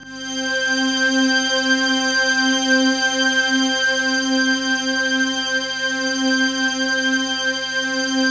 PAD3  C4  -L.wav